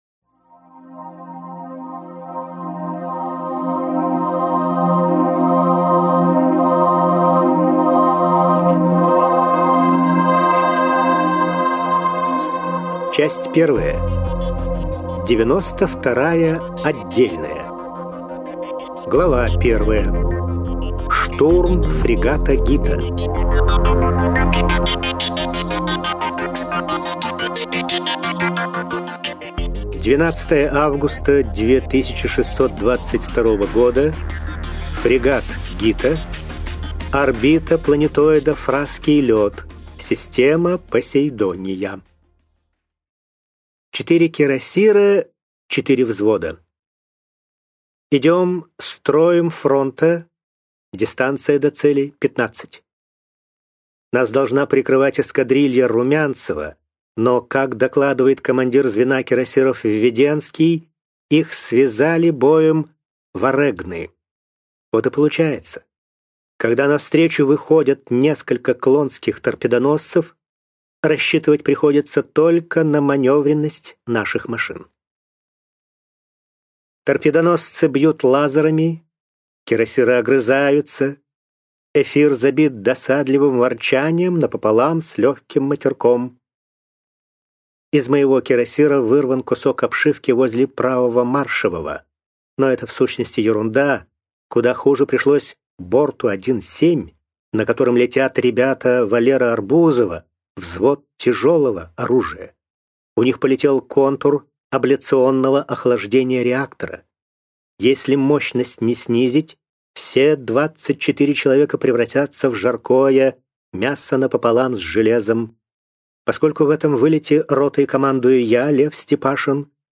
Аудиокнига На корабле утро | Библиотека аудиокниг